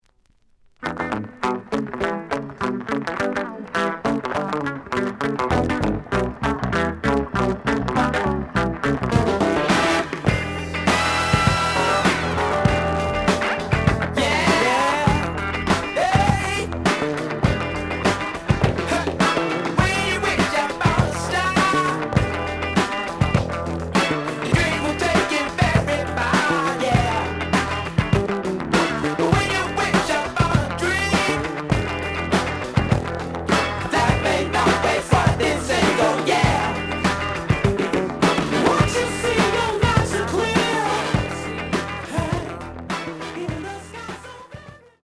The audio sample is recorded from the actual item.
●Genre: Funk, 70's Funk
Looks good, but slight noise on A side.)